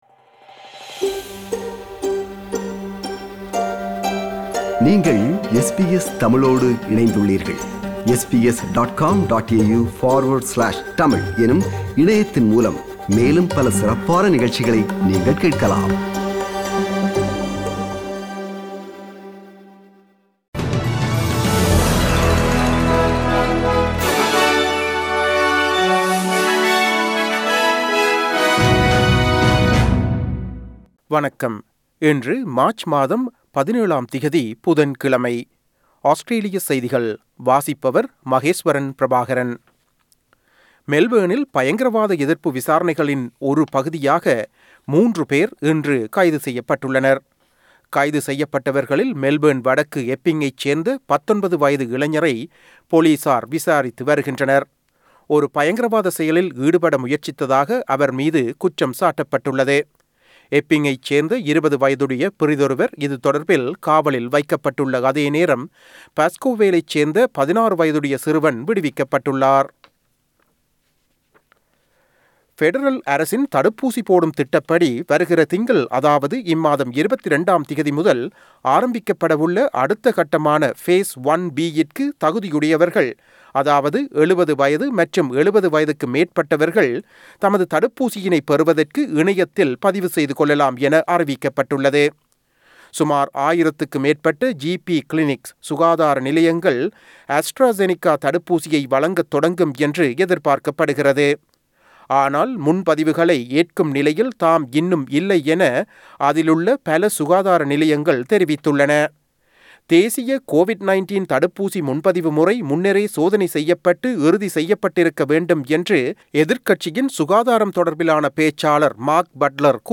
Australian news bulletin for Wednesday 17 March 2021.